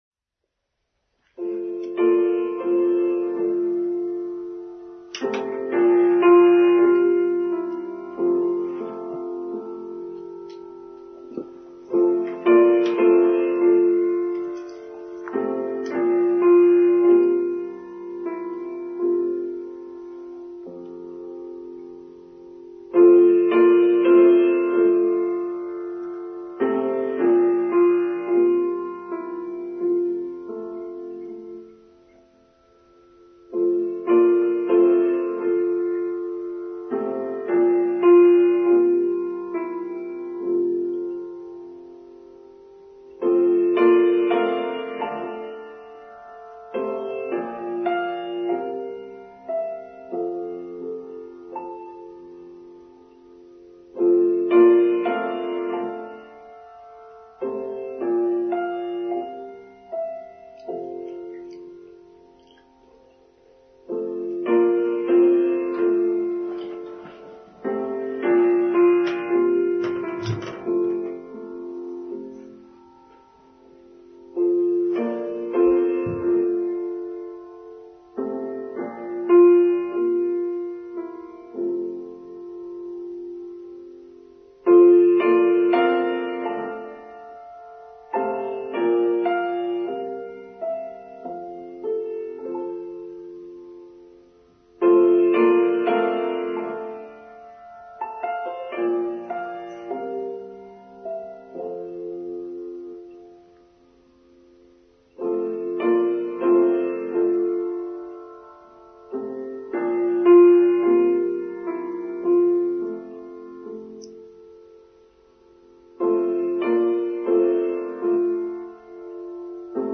Harvest of Life: The Parable of the Sower, Online Service for Sunday 11th September 2022